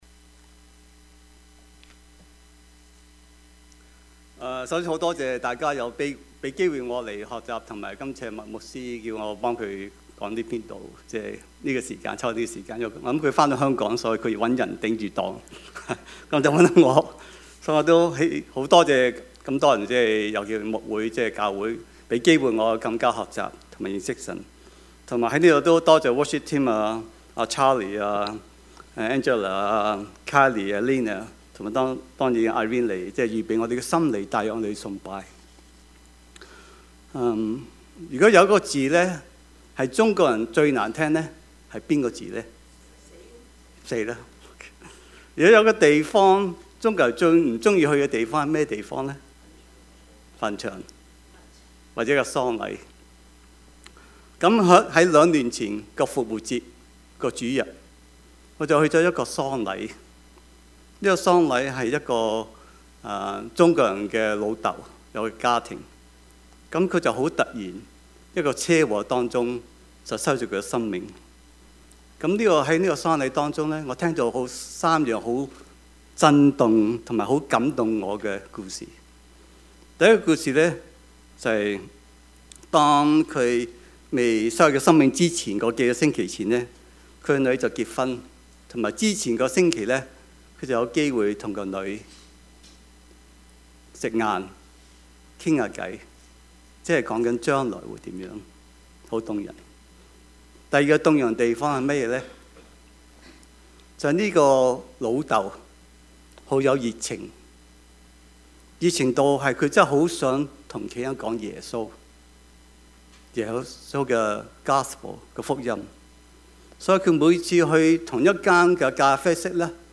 Service Type: 主日崇拜
Topics: 主日證道 « 恩 多結果子 »